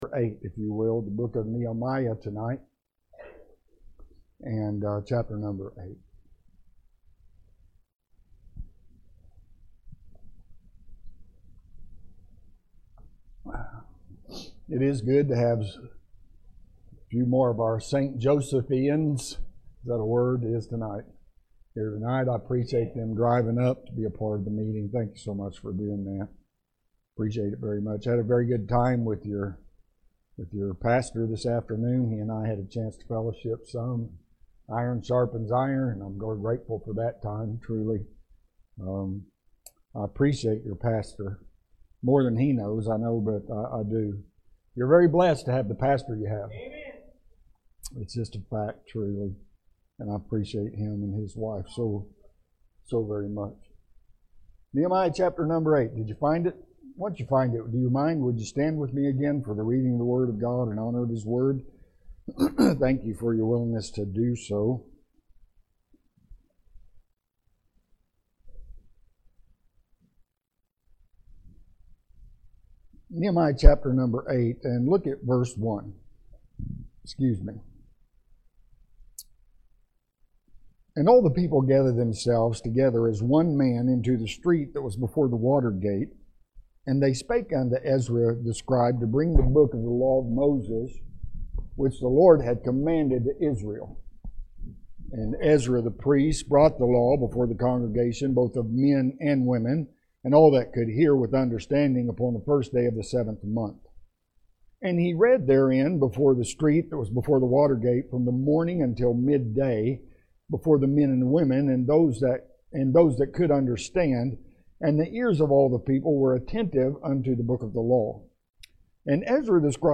Nehemiah 8 – TPM – Spring Revival